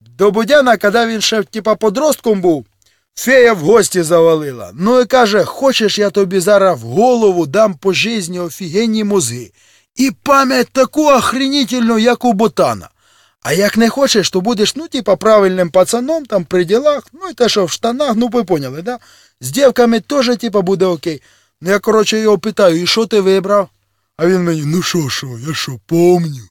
Копав файли Тіні Чорнобиля, відкопав озвучку, і курво: такі опрутненні жарти західняцьким діялектом, що пиздець.